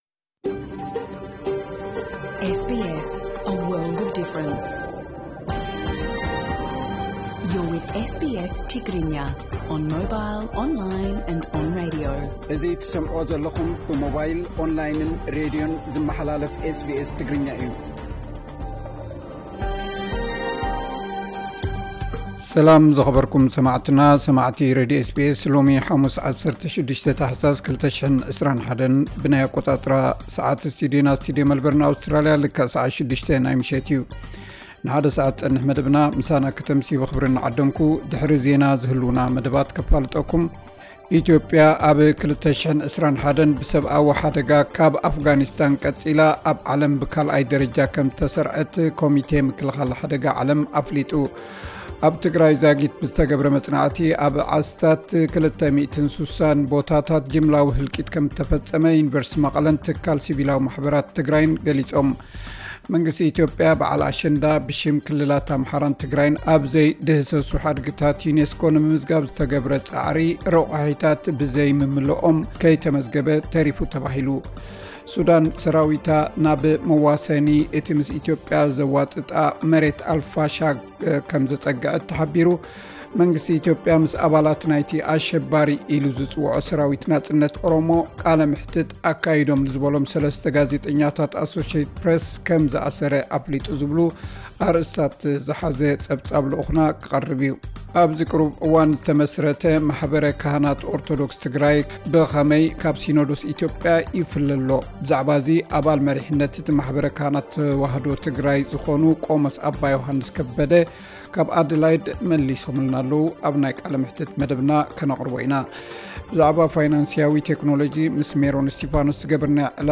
ዕለታዊ ዜና SBS ትግርኛ (16 ታሕሳ 2021)